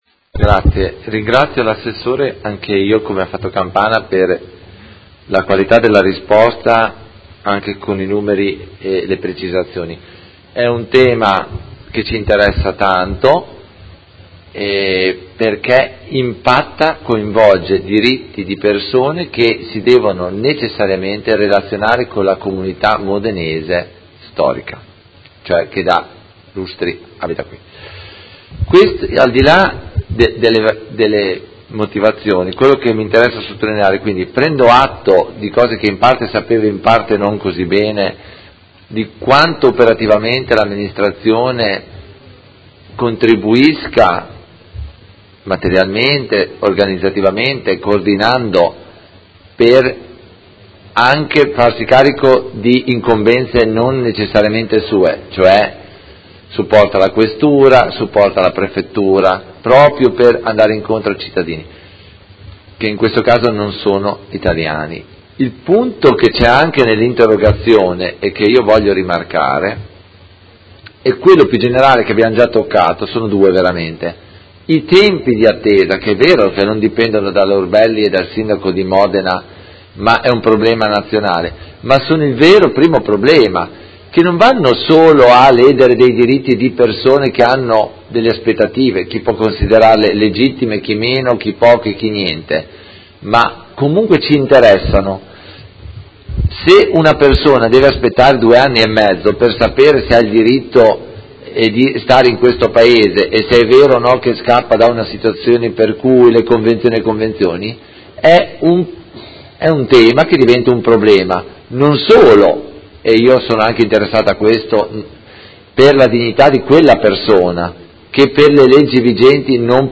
Seduta del 23/11/2007 Replica a risposta Assessore Urbelli. Interrogazione dei Consiglieri Fasano e Carpentieri (PD) avente per oggetto: Protesta dei cittadini migranti e risposta delle istituzioni modenesi – Primo firmatario Consigliere Fasano.